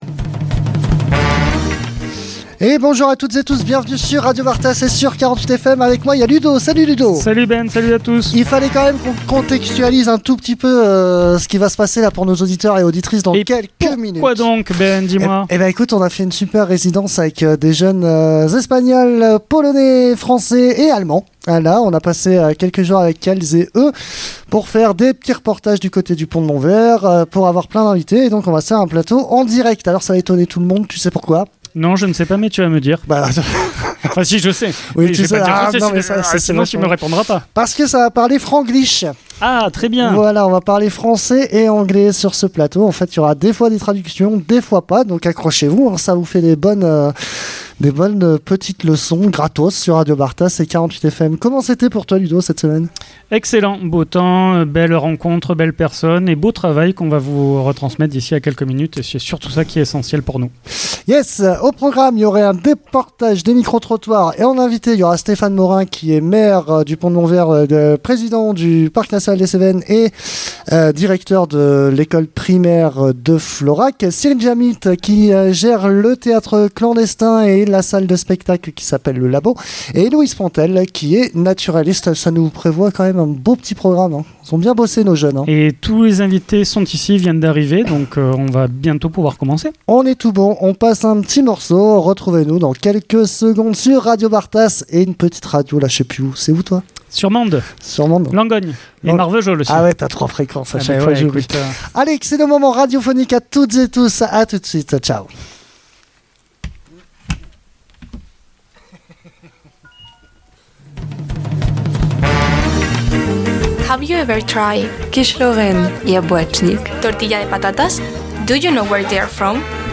Plateau radio réalisé en partenariat avec les jeunes lors de leur séjour au Réseau d'Entraide volontaire, 48 FM et Radio Bartas.